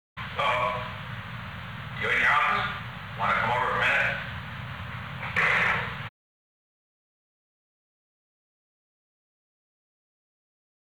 On June 21, 1973, President Richard M. Nixon and J. Fred Buzhardt, Jr. met in the President's office in the Old Executive Office Building from 5:21 pm to 5:22 pm. The Old Executive Office Building taping system captured this recording, which is known as Conversation 447-038 of the White House Tapes.